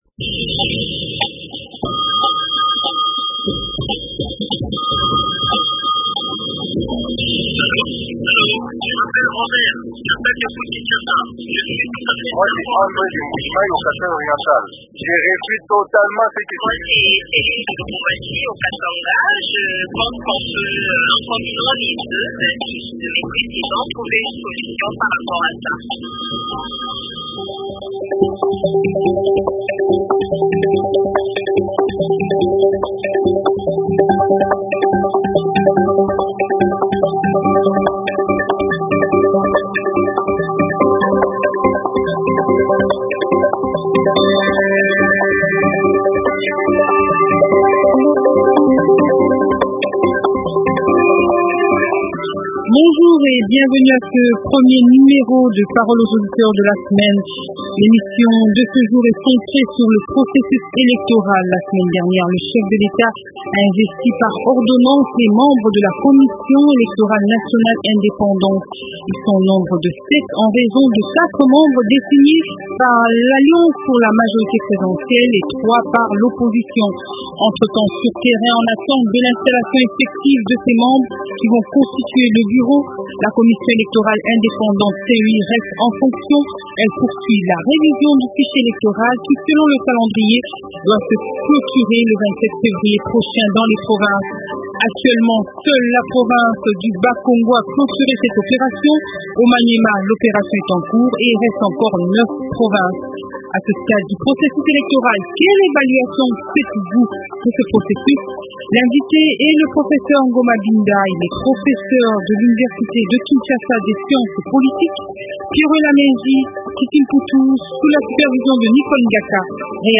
A ce stade, quelle évaluation faites-vous du processus électoral en RDC? L’invité :